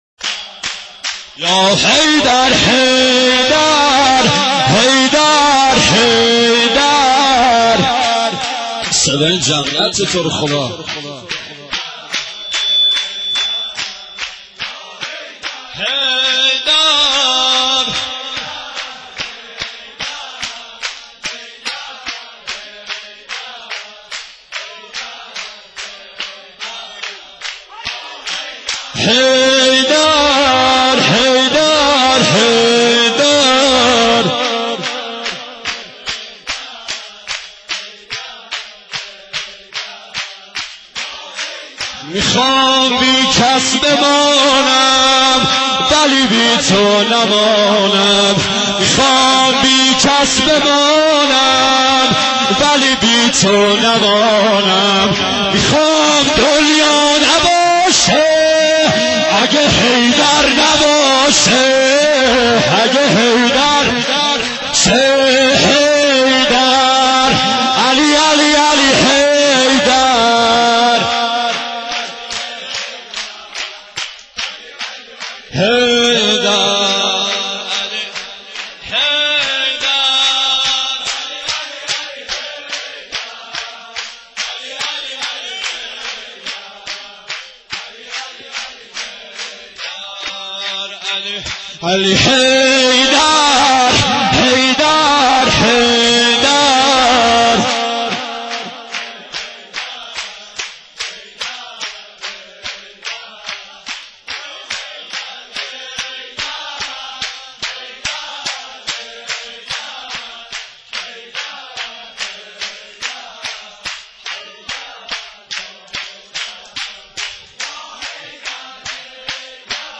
برچسب ها: مولودی ، مداحی ، فضای مجازی ، ایکنا